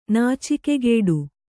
♪ nācikegēḍu